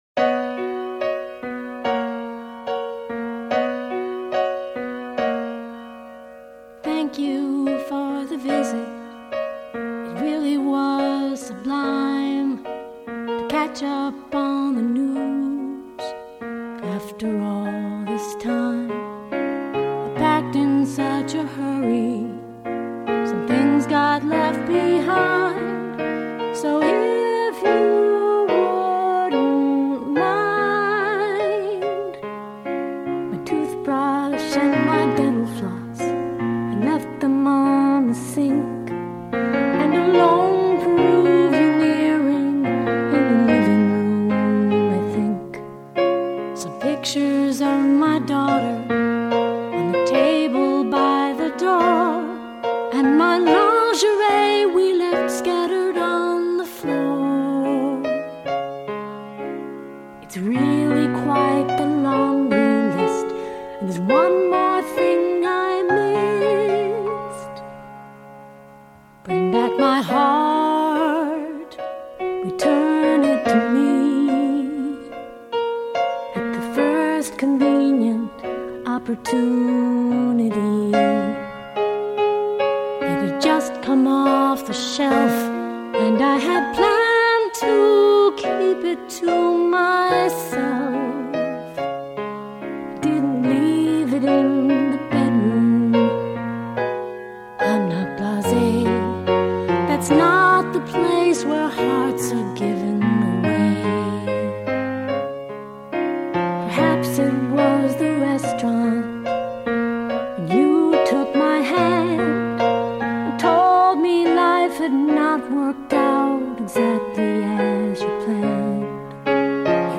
You can hear me sing it if you click on the link above.
Hope you enjoy this little love song, recorded at the studio